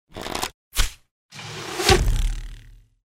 Звуки лука, стрел
Стрельба из лука в цель